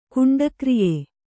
♪ kuṇḍakriye